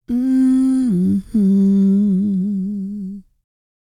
E-CROON P306.wav